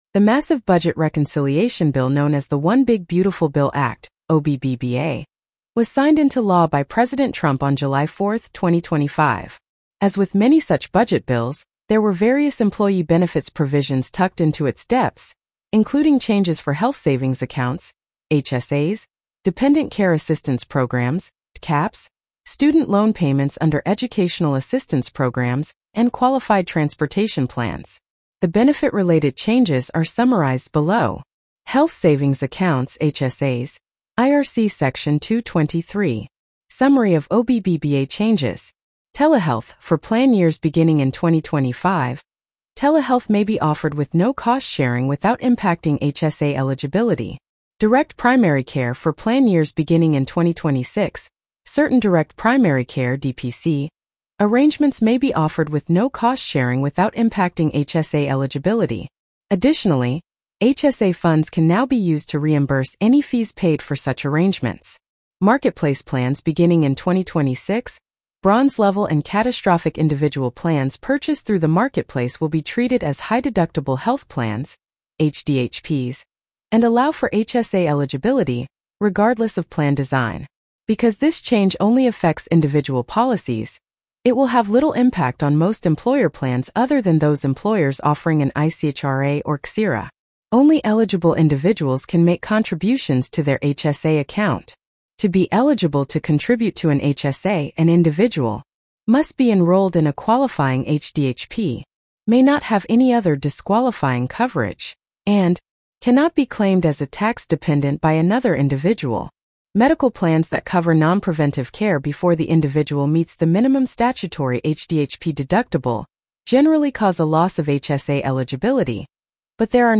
One Big Beautiful Bill Act (OBBBA) Blog Narration.mp3